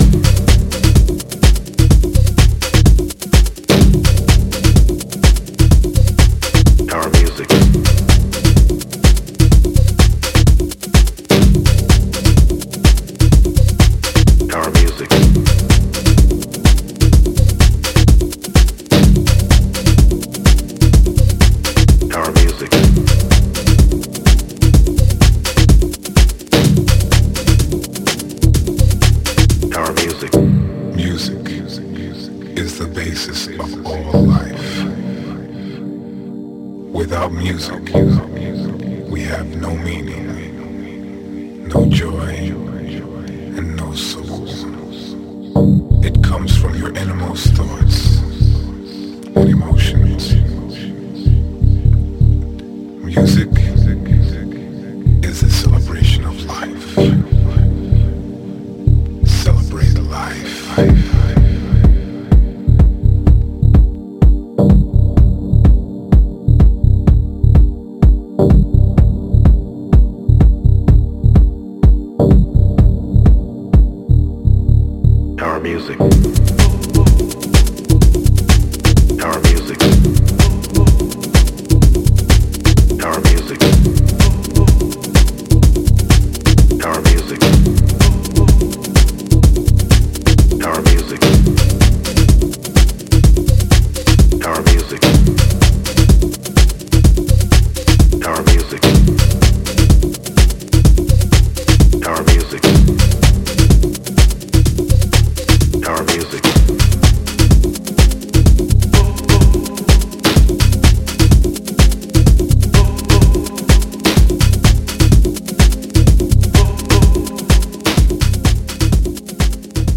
がっちりとしたボトム・グルーヴを備えた渋い秀作